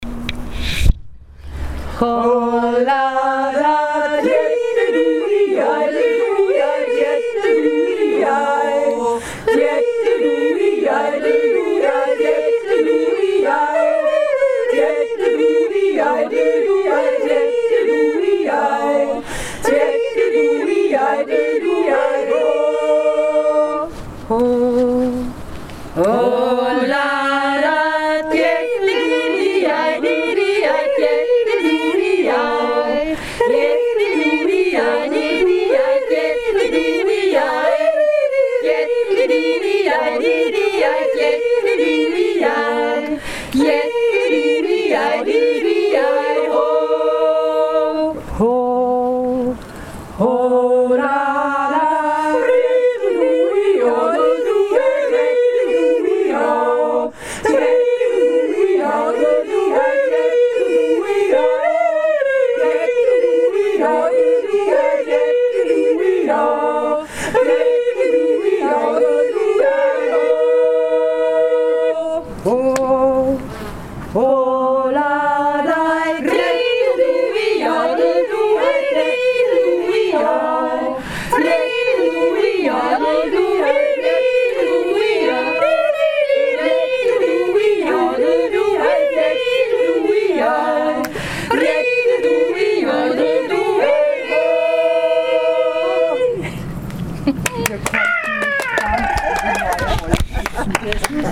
So jodelten wir auf den Rimpf 2016 - aufgeaht`s